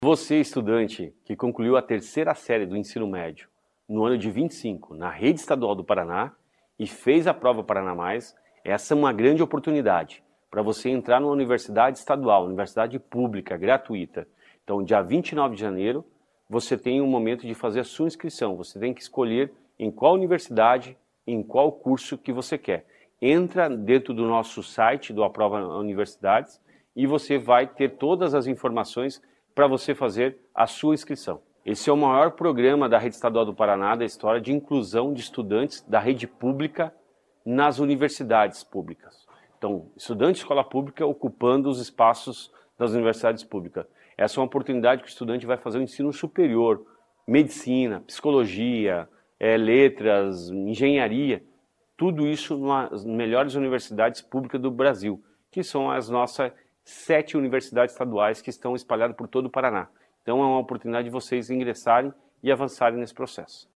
Sonora do secretário da Educação, Roni Miranda, sobre o Aprova Paraná Universidades